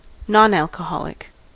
Ääntäminen
Vaihtoehtoiset kirjoitusmuodot (brittienglanti) non-alcoholic Ääntäminen US Haettu sana löytyi näillä lähdekielillä: englanti Käännöksiä ei löytynyt valitulle kohdekielelle.